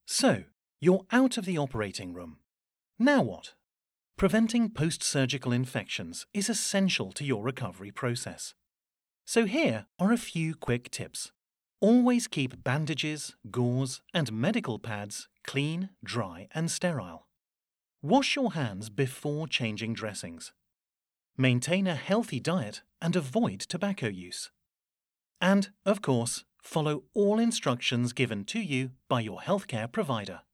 English (British)
Baritone
WarmFriendlyReliableAssuredEngaging